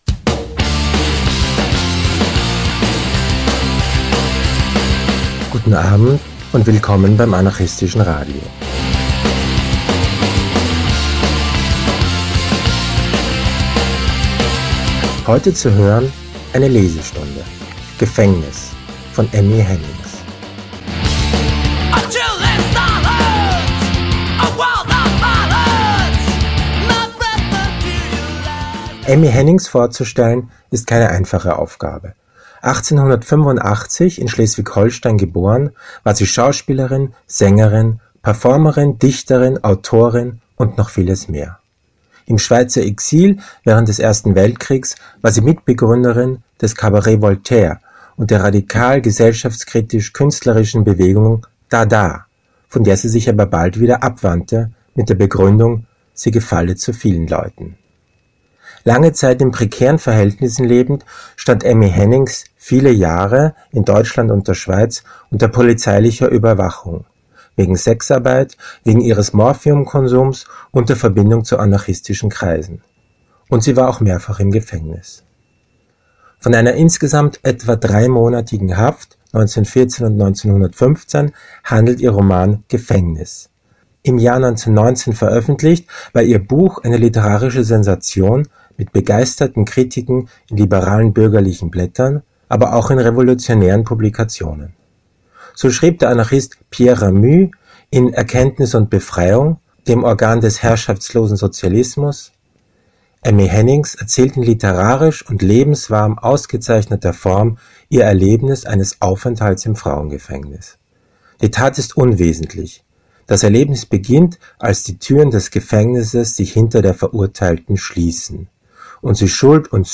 IntroHörbuch “Gefängnis”